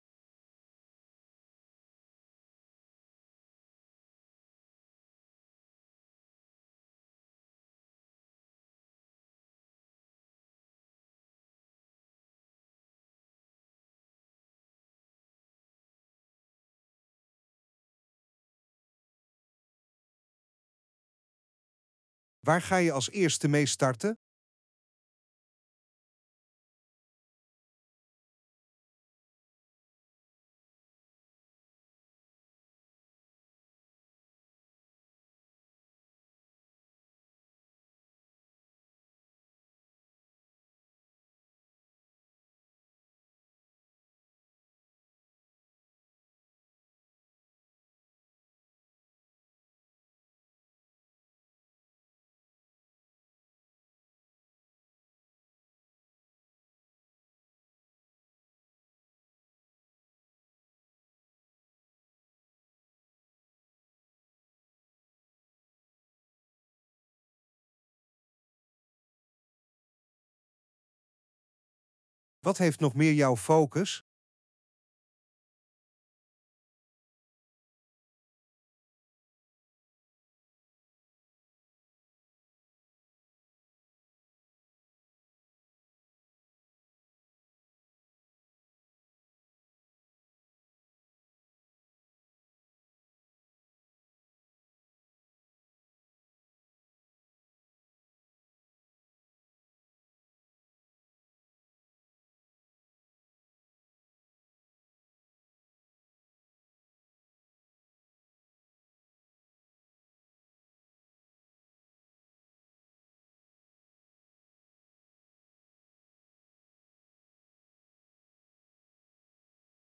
Quote van Marjolein Faber, minister van Asiel en Migratie, over asielmigratie.